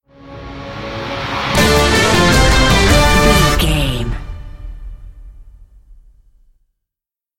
Ionian/Major
D
horns
drums
electric guitar
synthesiser
orchestral
orchestral hybrid
dubstep
aggressive
energetic
intense
powerful
strings
bass
synth effects
wobbles
heroic
driving drum beat
epic